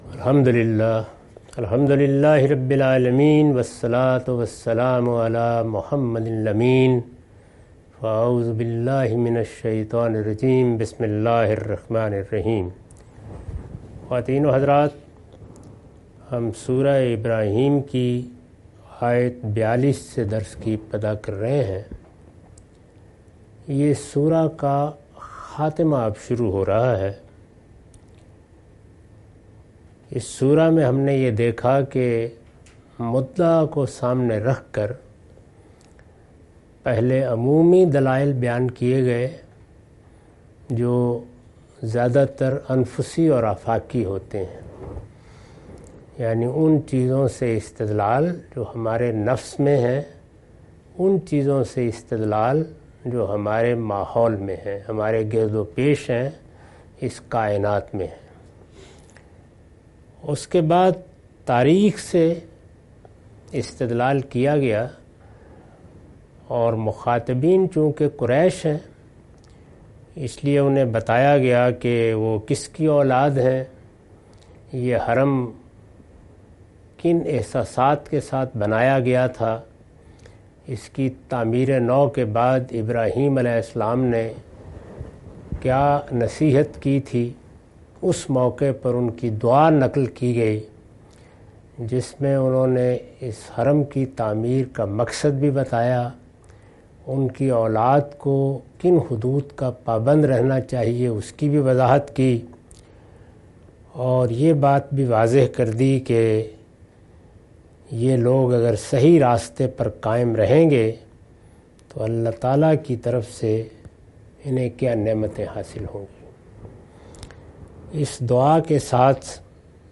Surah Ibrahim- A lecture of Tafseer-ul-Quran – Al-Bayan by Javed Ahmad Ghamidi. Commentary and explanation of verses 42-52.